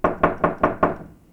doorknock.wav